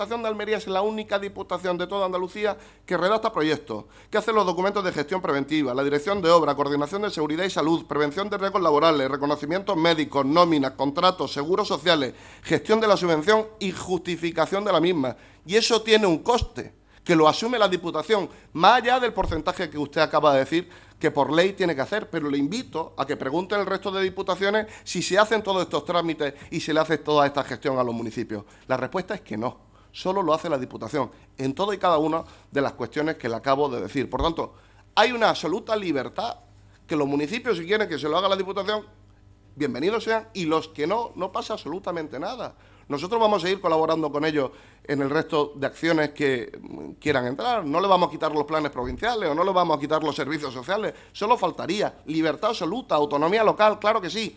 23-09_pleno_fernando_gimenez.mp3